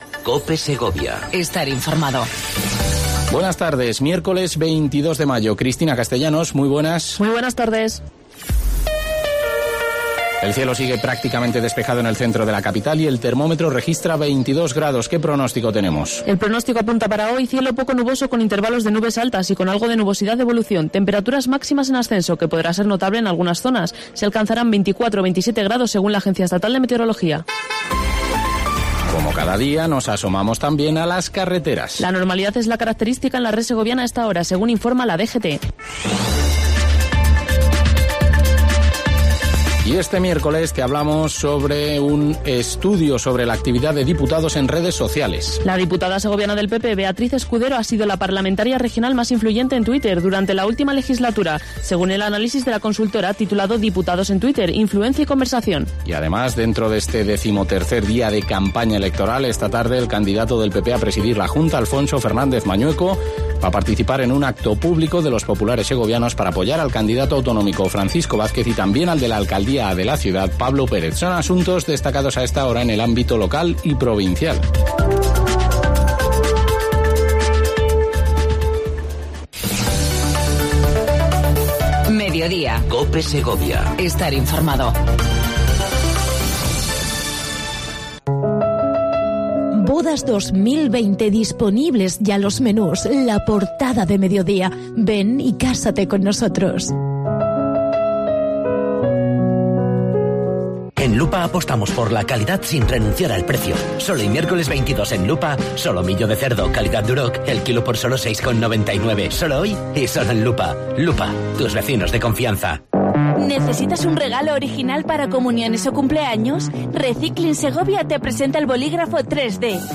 AUDIO: Entrevista a Francisco Vázquez Requero, Presidente de la Diputación Provincial de Segovia. VIII Edición Dibujo Ecológico Cope Segovia.